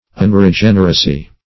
Search Result for " unregeneracy" : The Collaborative International Dictionary of English v.0.48: Unregeneracy \Un`re*gen"er*a*cy\, n. The quality or state of being unregenerate.
unregeneracy.mp3